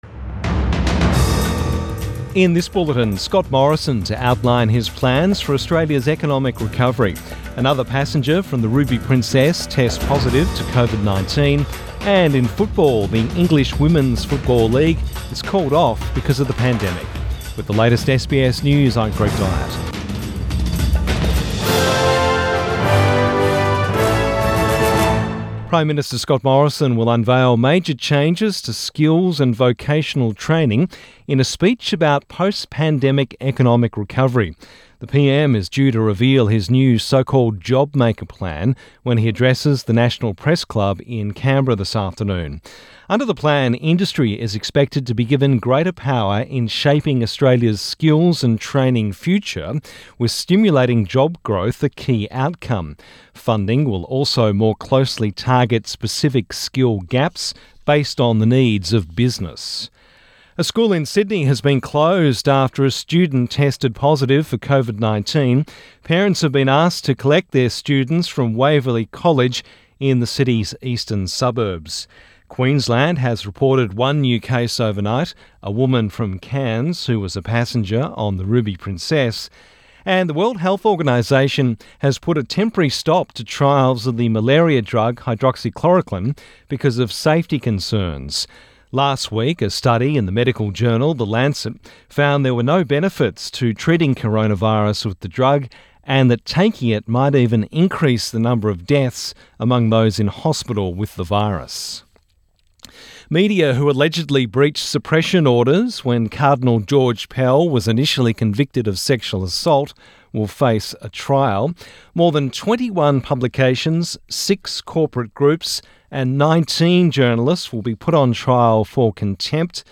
Midday bulletin 26 May 2020